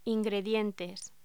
Locución: Ingredientes
voz